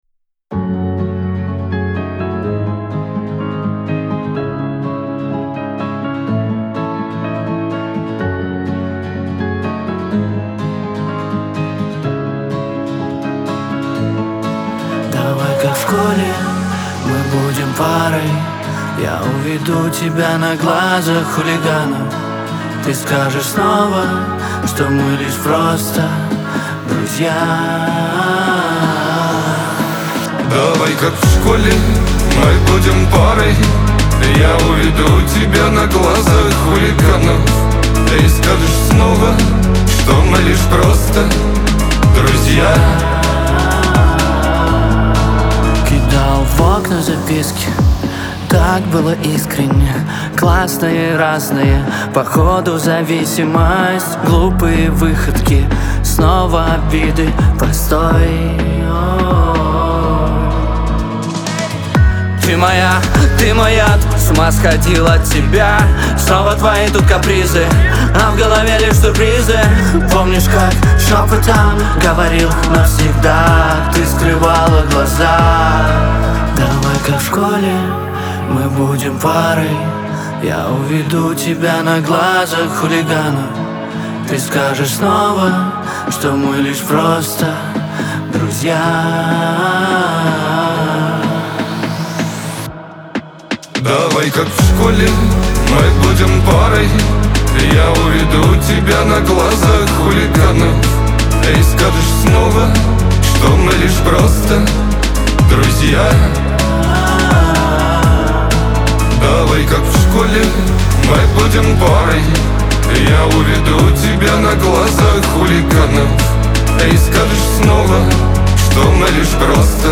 ХАУС-РЭП , Шансон
дуэт